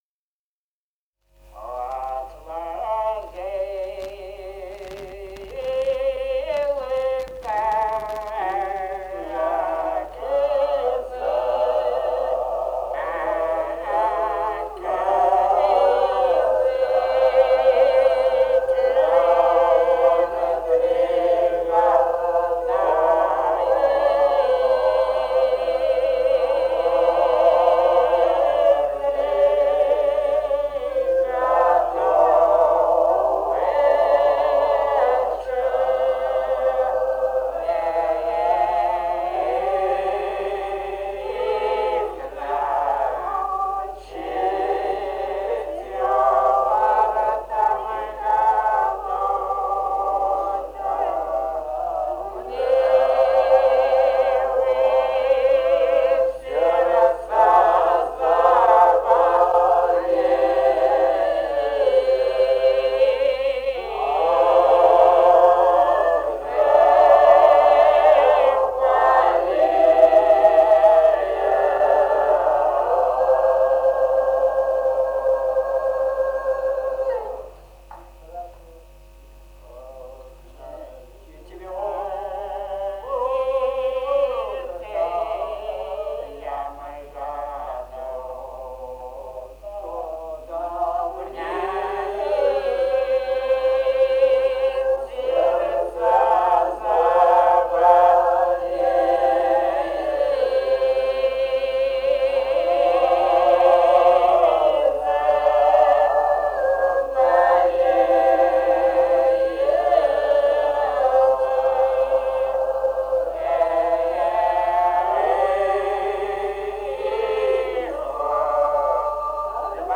полевые материалы
«Отслужил казак три года» (солдатская).
Грузия, с. Гореловка, Ниноцминдский муниципалитет, 1971 г. И1309-16